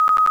notify.wav